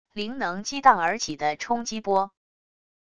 灵能激荡而起的冲击波wav音频